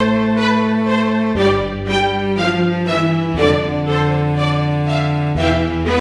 Here’s a snippet of a score: and attached here is the rendered audio that uses only staccato samples when the score indicates legato phrases.